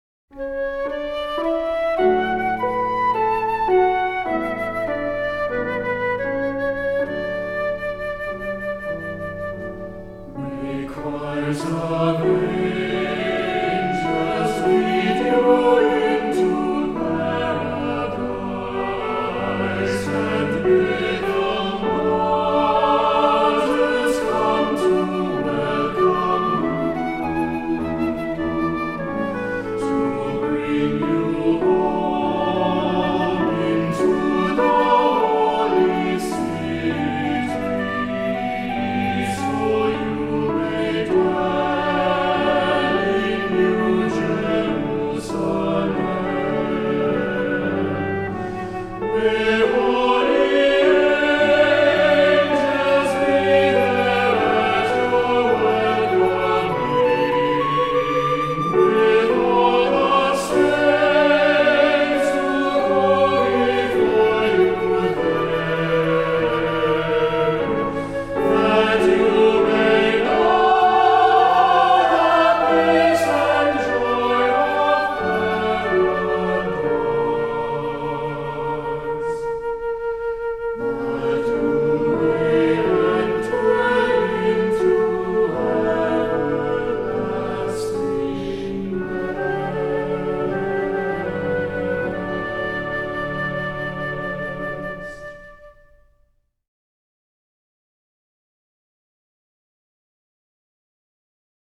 Voicing: Unison or Cantor; optional Descant; Assembly